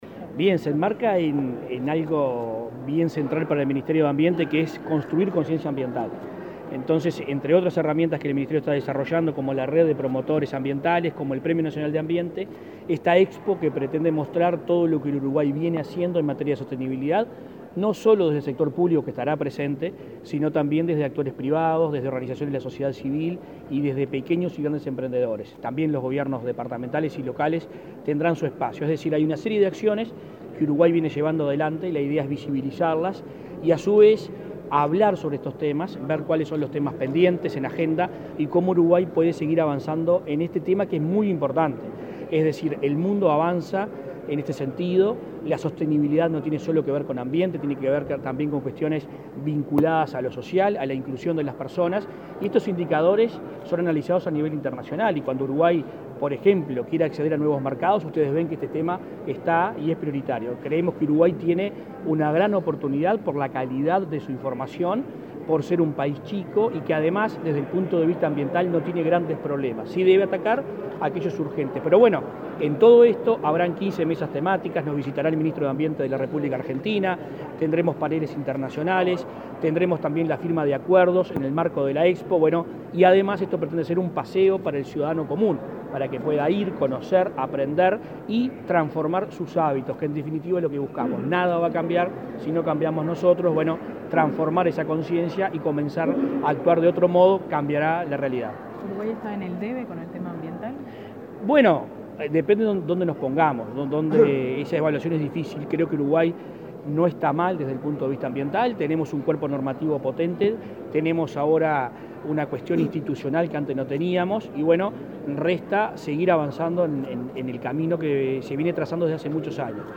Declaraciones a la prensa del ministro de Ambiente, Adrián Peña
Declaraciones a la prensa del ministro de Ambiente, Adrián Peña 25/05/2022 Compartir Facebook X Copiar enlace WhatsApp LinkedIn Este miércoles 25 en el Palacio Legislativo, se efectuó el lanzamiento de la Primera Expo Uruguay Sostenible, que se realizará entre el viernes 10 y el domingo 12 de junio en el velódromo municipal de Montevideo. Luego, el ministro de Ambiente, Adrián Peña, dialogó con la prensa.